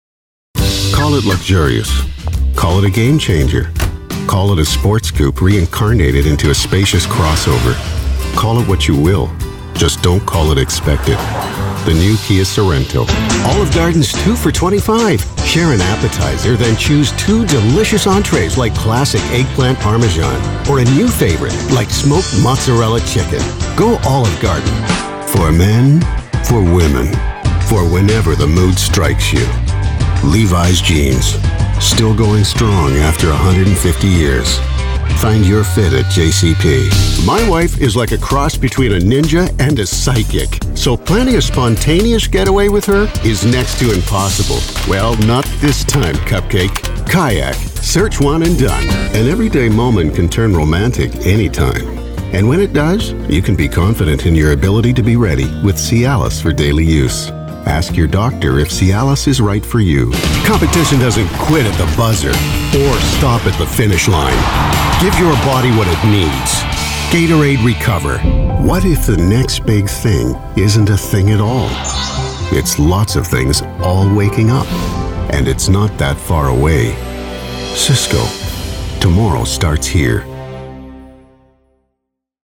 male voice over english north america usa canada confident deep narration announcer middle age confident
Sprechprobe: Werbung (Muttersprache):